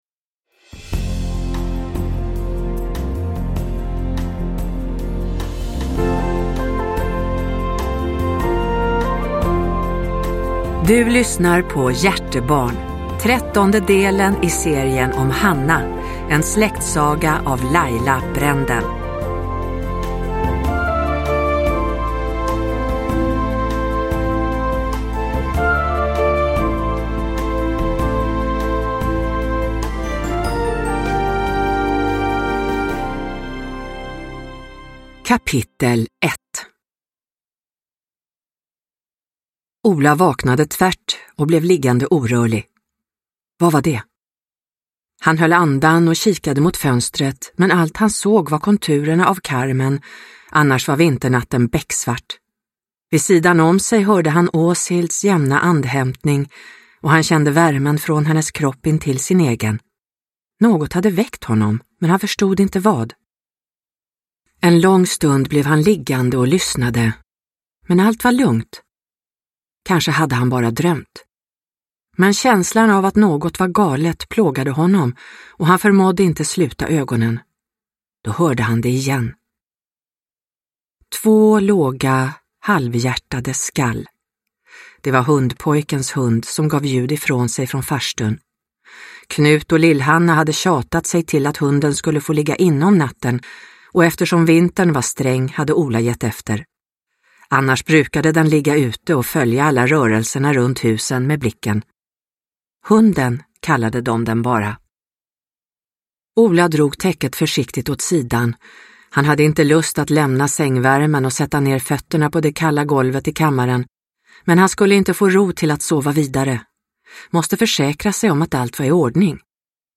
Hjärtebarn – Ljudbok – Laddas ner